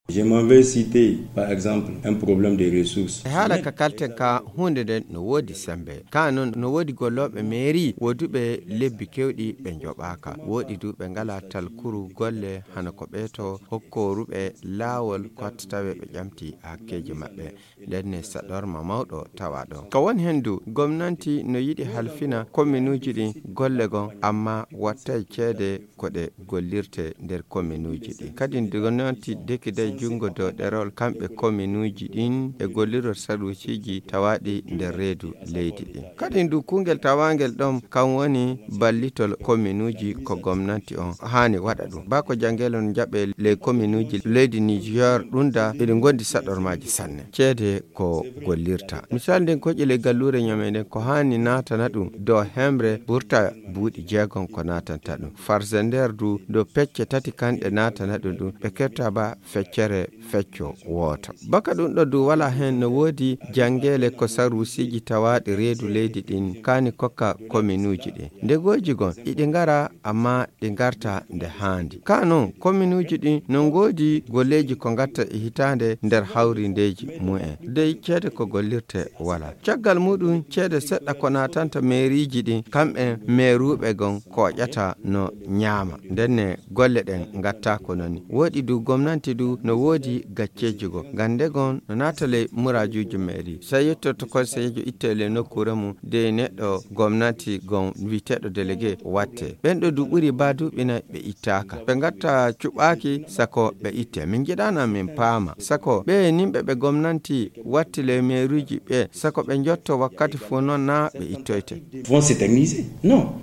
spécialiste en Décentralisation au micro